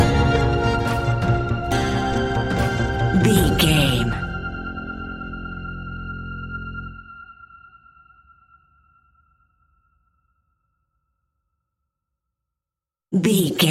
In-crescendo
Aeolian/Minor
ominous
suspense
eerie
synthesizer
horror music
Horror Synths